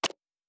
pgs/Assets/Audio/Sci-Fi Sounds/Mechanical/Device Toggle 03.wav at master
Device Toggle 03.wav